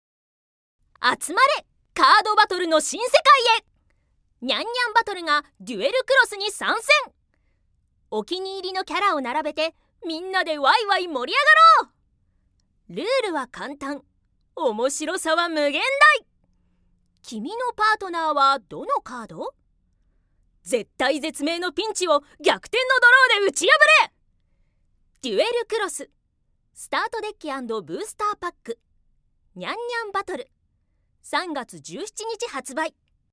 ◆カードゲームCM(明るめ)◆